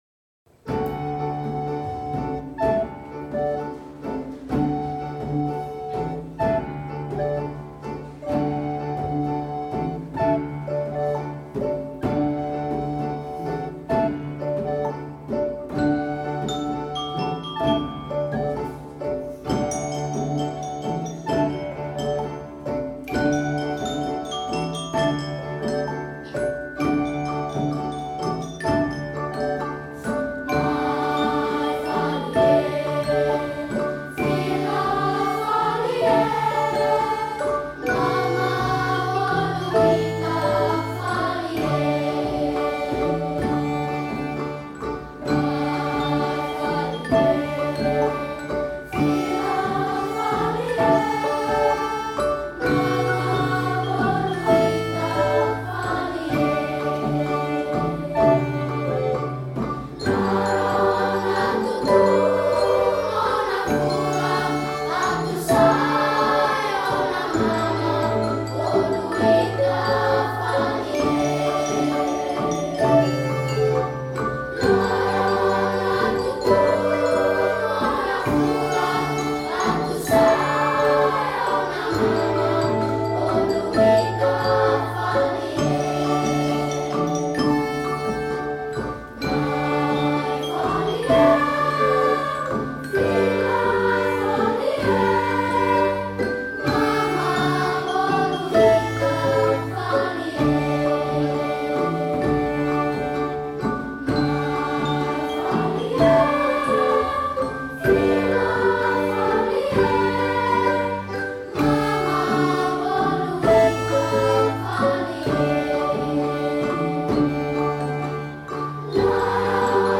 Este é, na continuidade do ano anterior, o resultado de um ano de interação e comunicação dos alunos da EPRC com a música.
Canção Tradicional Timorense